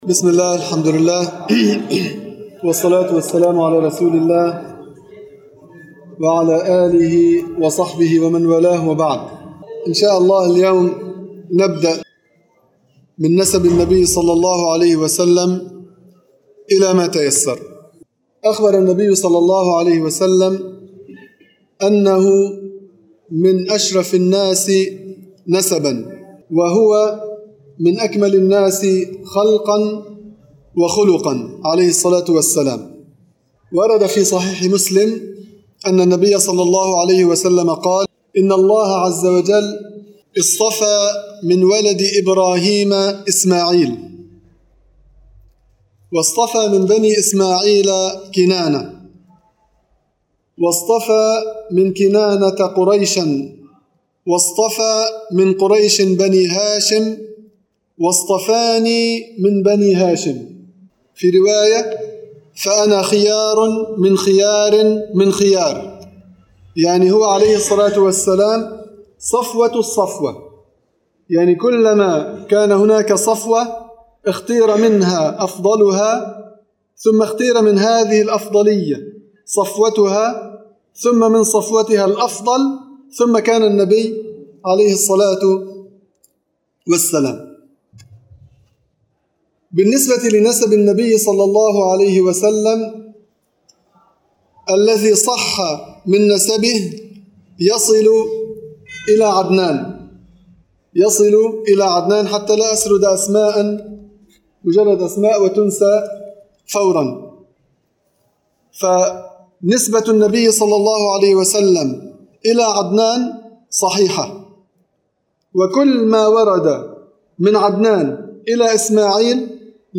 المكان: مسجد القلمون الغربي
سلسلة دروس السيرة النبوية